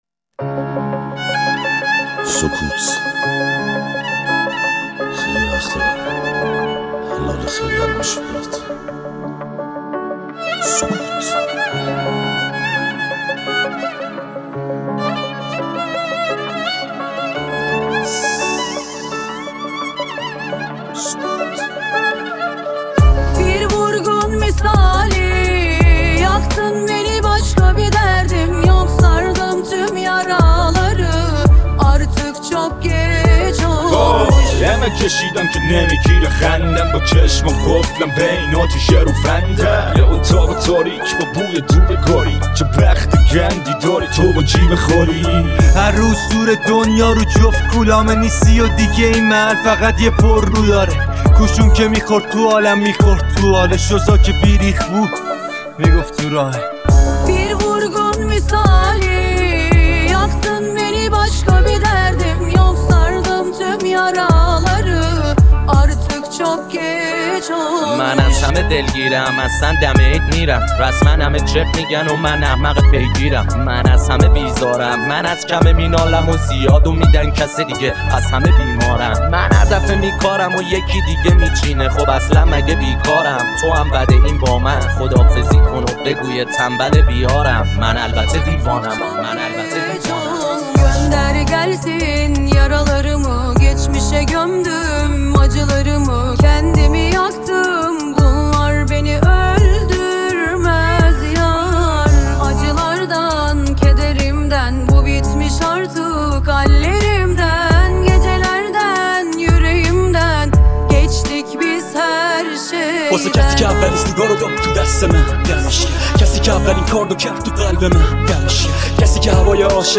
ریمیکس رپی
Remix Rapi